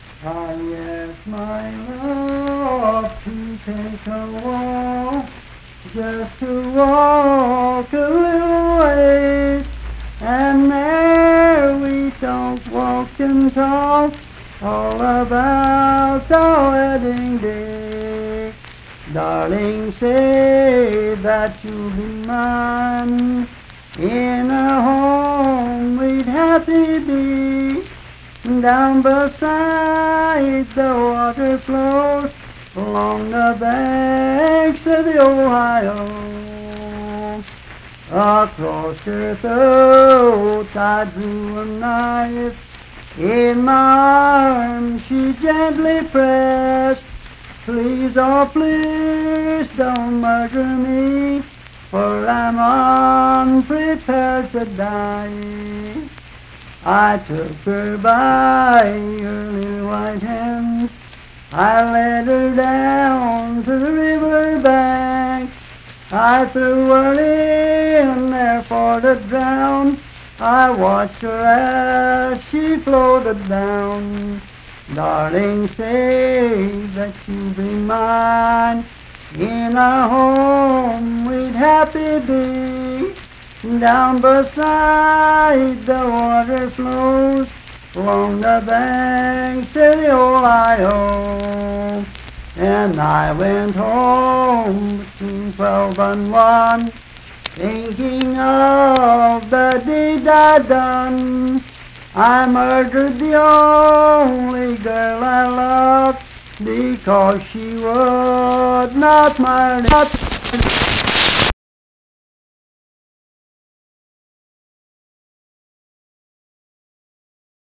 Unaccompanied vocal performance
Voice (sung)
Randolph County (W. Va.)